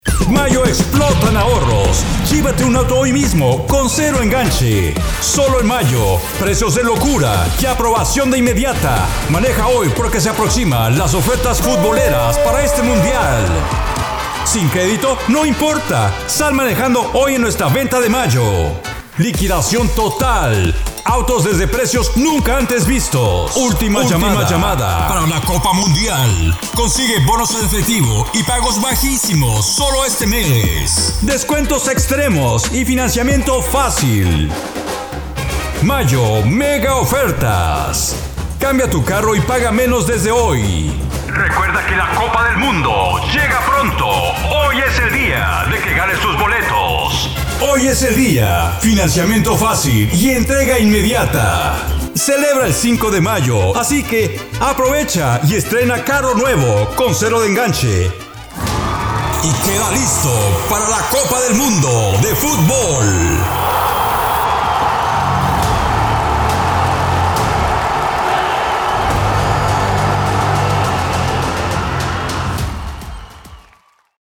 Never any Artificial Voices used, unlike other sites.
Adult (30-50)